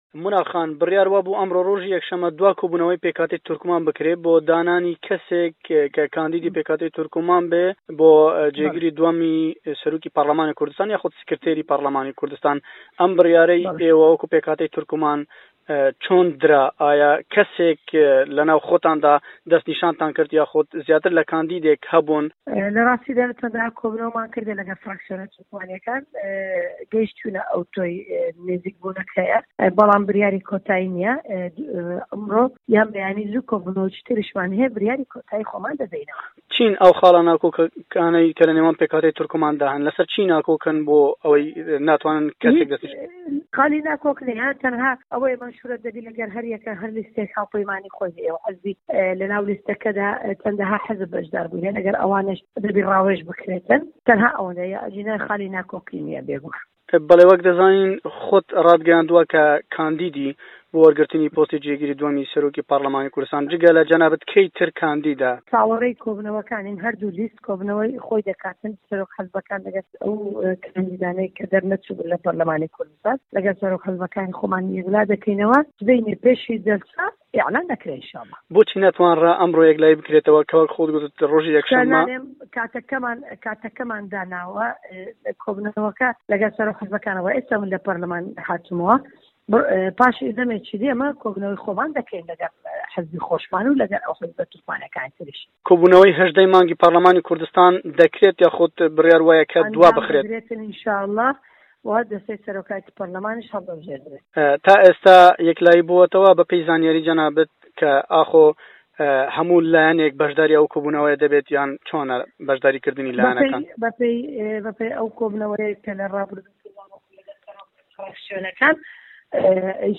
لە چاوپێكەوتنێكدا